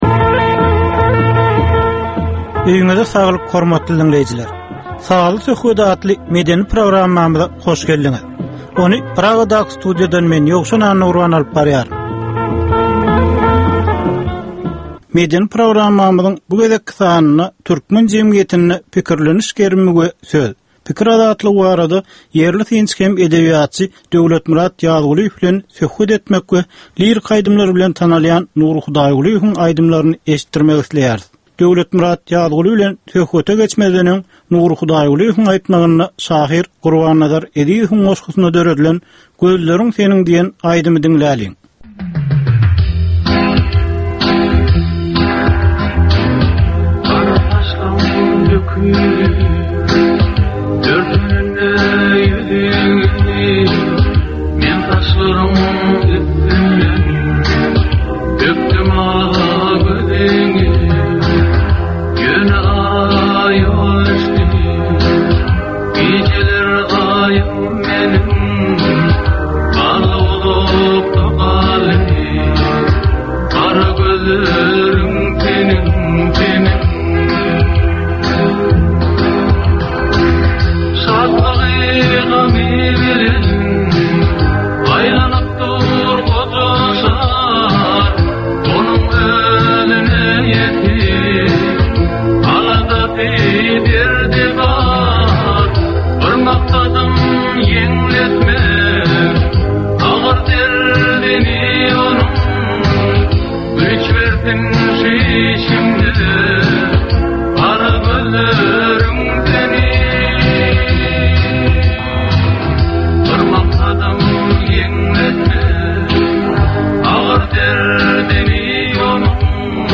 Türkmenin käbir aktual meseleleri barada 30 minutlyk sazly-informasion programma